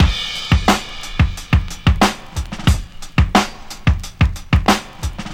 • 90 Bpm '00s Drum Loop C# Key.wav
Free drum beat - kick tuned to the C# note. Loudest frequency: 1107Hz
90-bpm-00s-drum-loop-c-sharp-key-VIT.wav